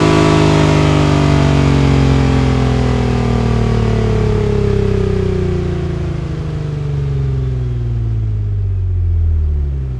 rr3-assets/files/.depot/audio/Vehicles/ttv6_02/ttv6_02_decel.wav
ttv6_02_decel.wav